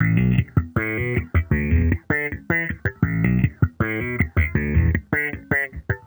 Index of /musicradar/sampled-funk-soul-samples/79bpm/Bass
SSF_JBassProc2_79B.wav